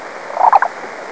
3. Black-billed Cuckoo
bb_cuckoo.wav